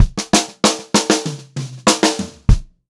96POPFILL3-L.wav